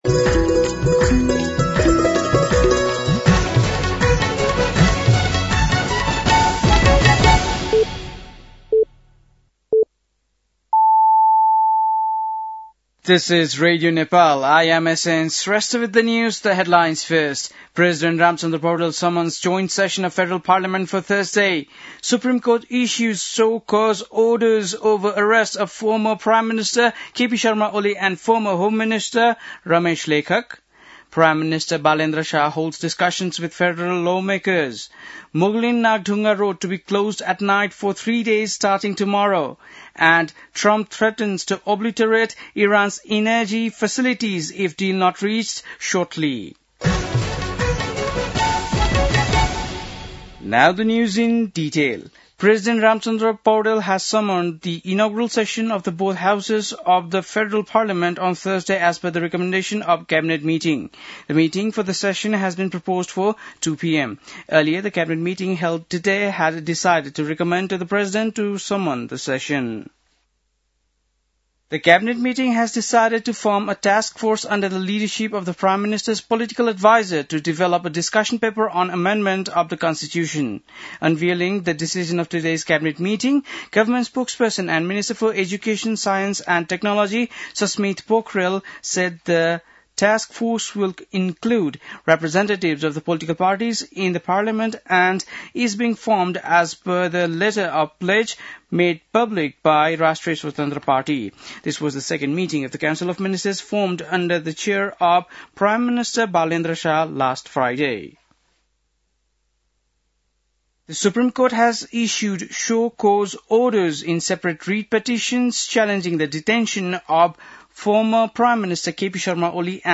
बेलुकी ८ बजेको अङ्ग्रेजी समाचार : १६ चैत , २०८२